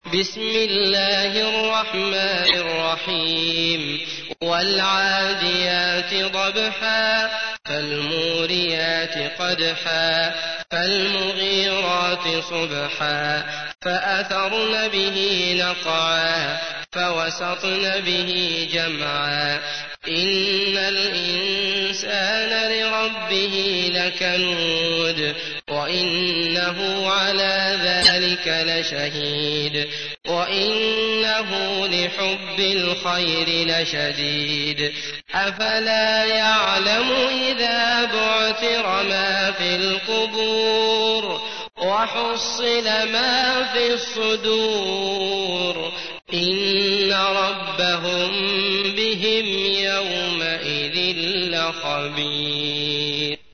تحميل : 100. سورة العاديات / القارئ عبد الله المطرود / القرآن الكريم / موقع يا حسين